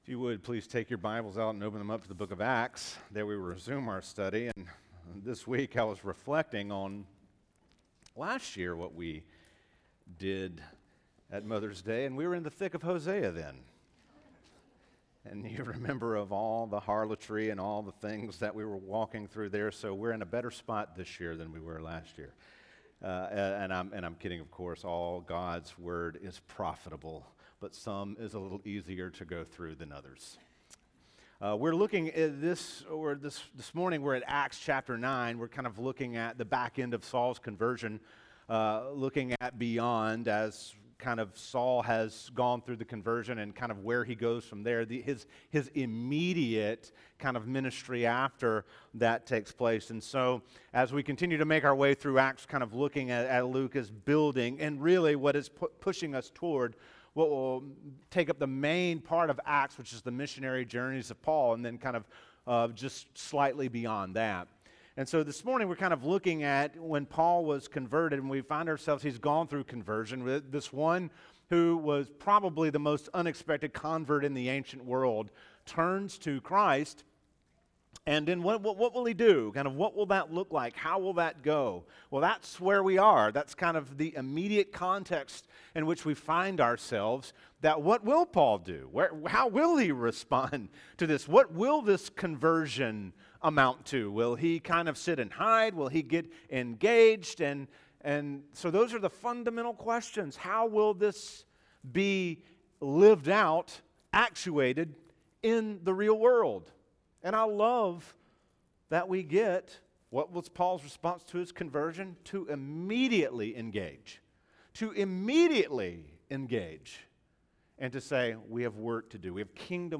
teaches from the series: Acts, in the book of Acts, verses 9:19 - 9:31